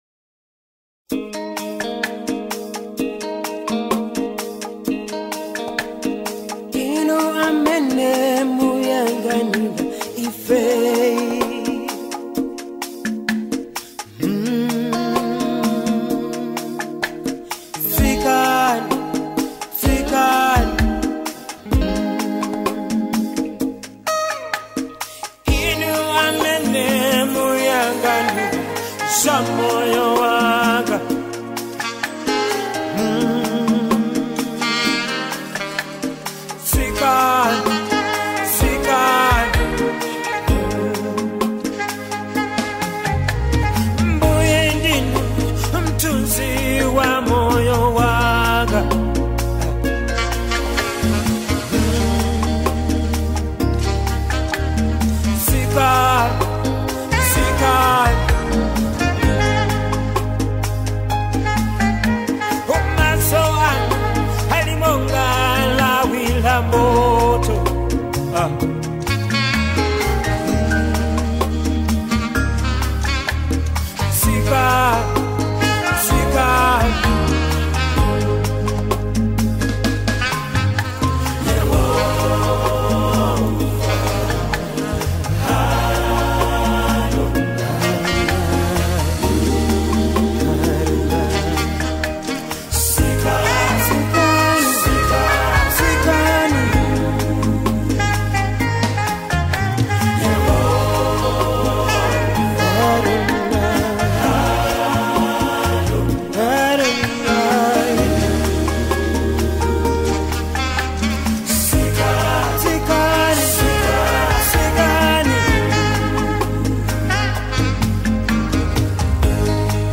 With its soul-stirring lyrics and captivating melody